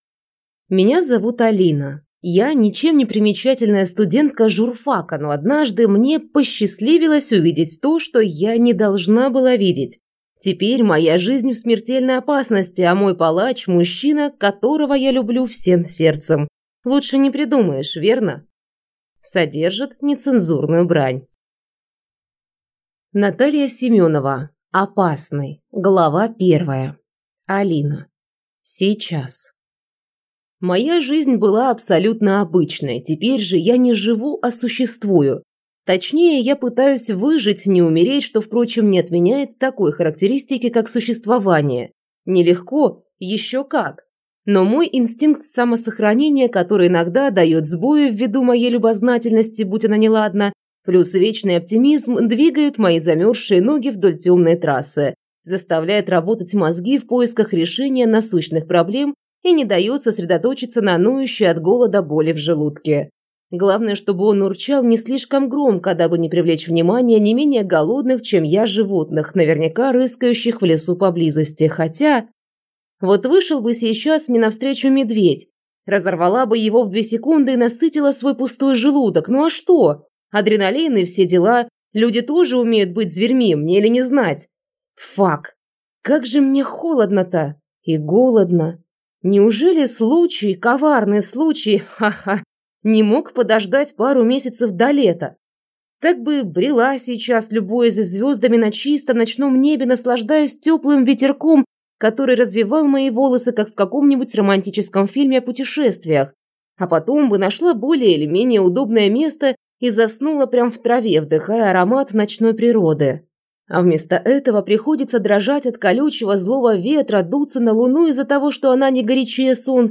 Аудиокнига Опасный | Библиотека аудиокниг